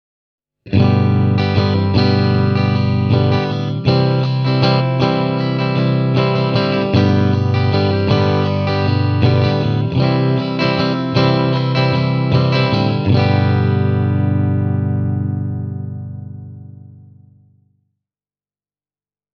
PUHDAS STRATO
Referenssikaiutin (Bluetone Shadows Jr. -kombo 10-tuumaisella WGS Green Beret -kaiuttimilla; Shure SM57):
wgs-green-beret-e28093-clean-strat.mp3